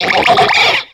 Cri de Croâporal dans Pokémon X et Y.